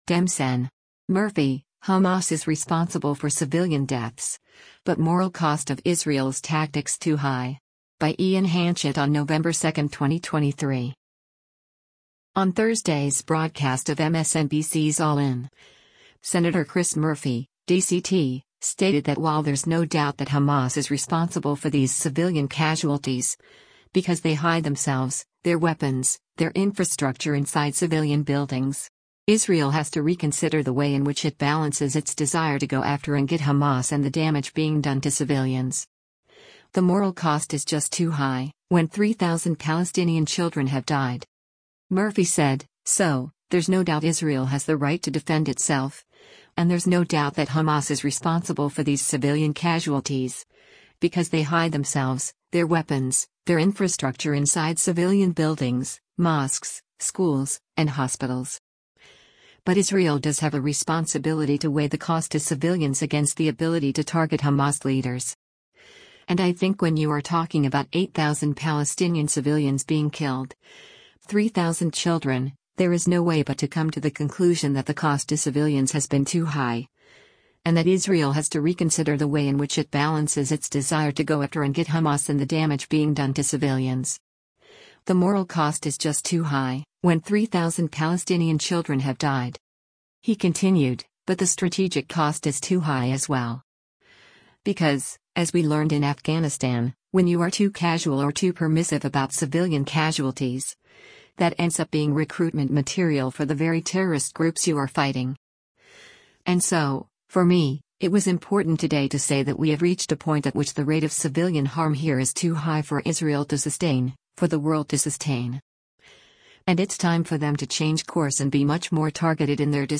On Thursday’s broadcast of MSNBC’s “All In,” Sen. Chris Murphy (D-CT) stated that while “there’s no doubt that Hamas is responsible for these civilian casualties, because they hide themselves, their weapons, their infrastructure inside civilian buildings,” “Israel has to reconsider the way in which it balances its desire to go after and get Hamas and the damage being done to civilians. The moral cost is just too high, when 3,000 Palestinian children have died.”